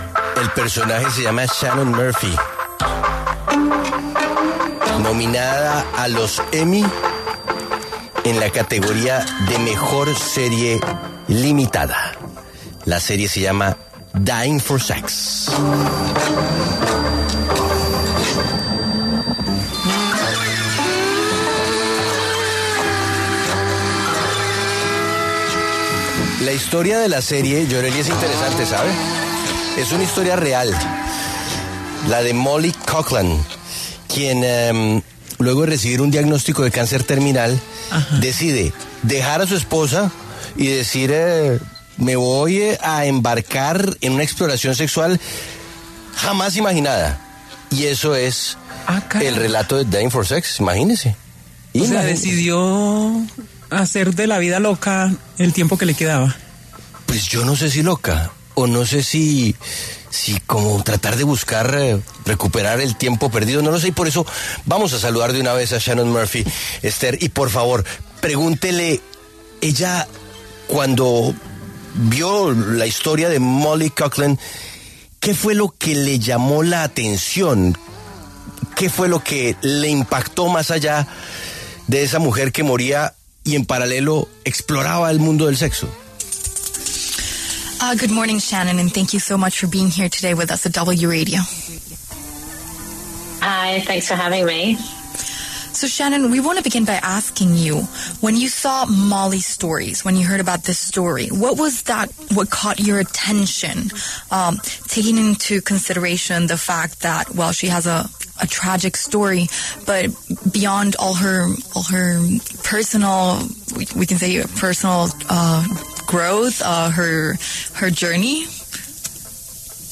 La directora australiana Shannon Murphy habló con La W sobre la serie ‘Dying for Sex’, nominada a los Emmy 2025 en la categoría Mejor Serie Limitada o Antología.